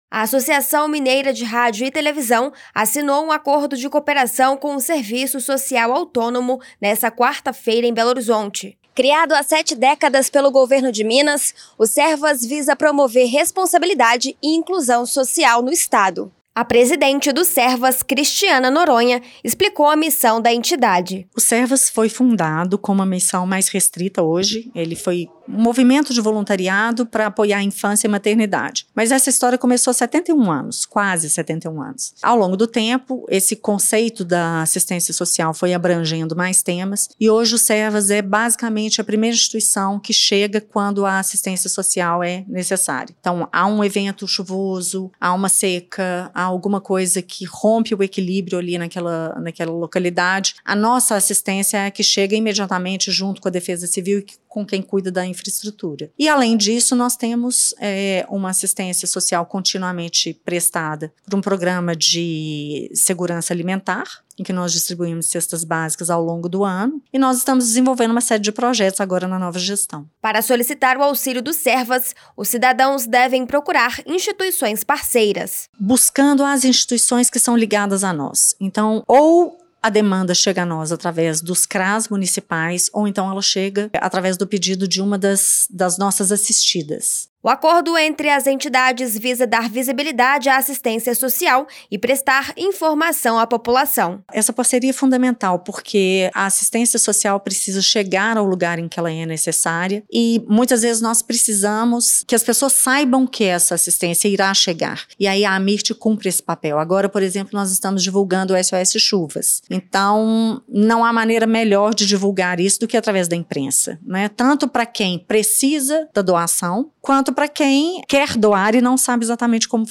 SERVAS-RADIO.mp3